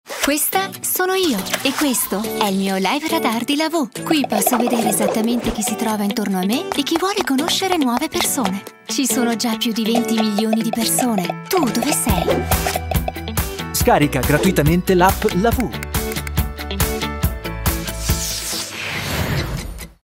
Sprechprobe: Werbung (Muttersprache):
I am an educated actress and I speak with a broad italian accent, what makes my speach very clear and articulately.
I have a joung, beautiful and light voice.